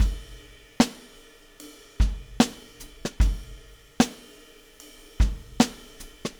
Weathered Beat 06.wav